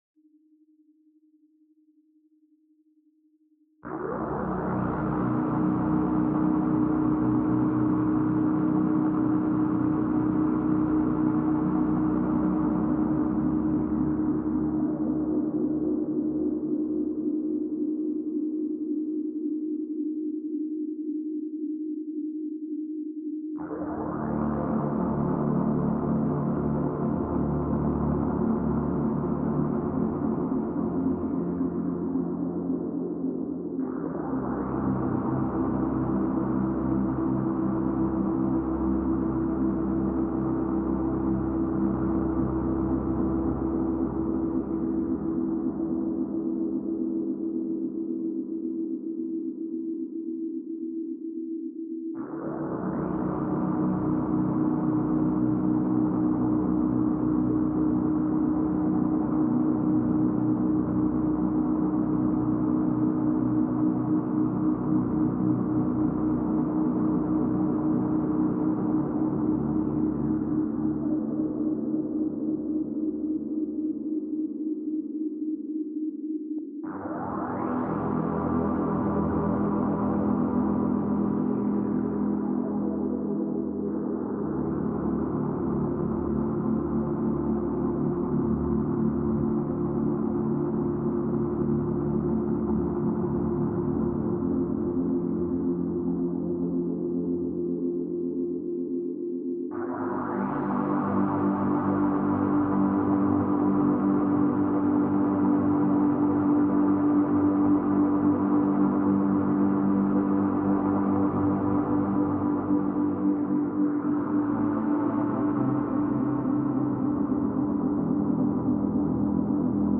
Recorded live at home in Harlem, NYC December 22, 2025